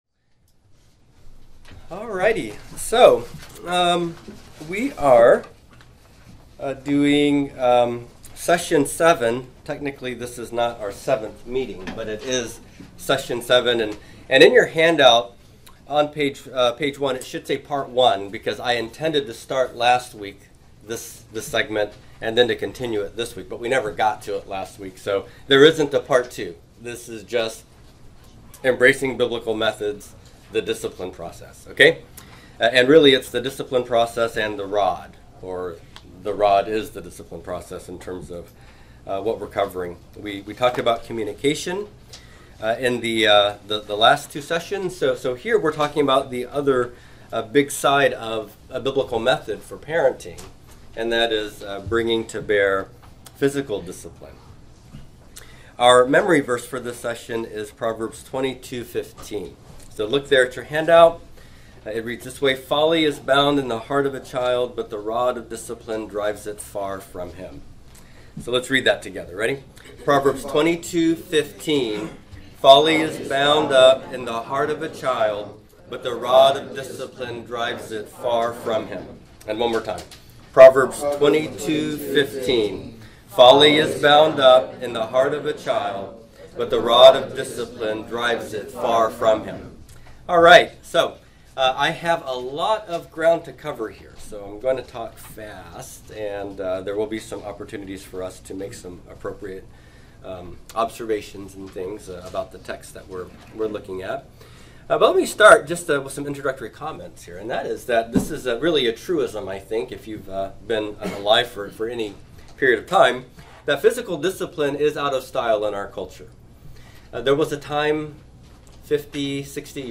Below are links to M4As of the lessons for the elective class, Foundations of Christian Parenting, at Founders Baptist Church. Generally, this class summarizes and teaches the theology and principles from Tedd Tripp’s book, Shepherding a Child’s Heart.